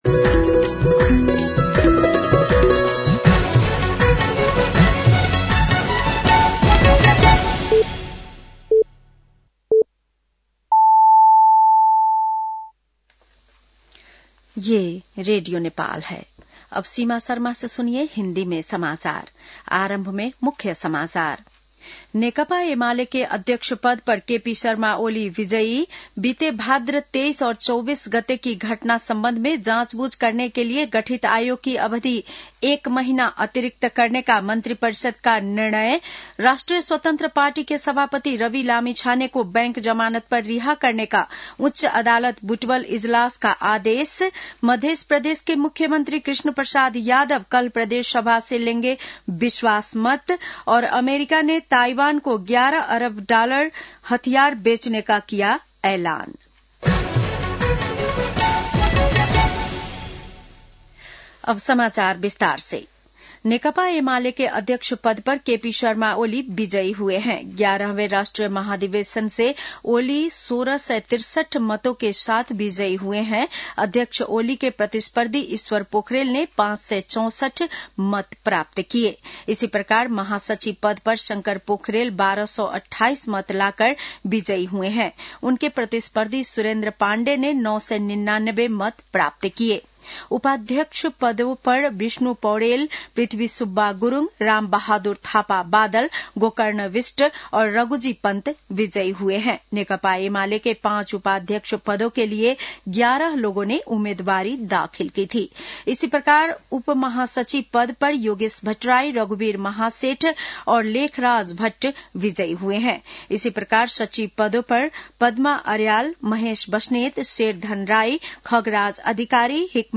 बेलुकी १० बजेको हिन्दी समाचार : ३ पुष , २०८२
10-PM-Hindi-NEWS-9-3.mp3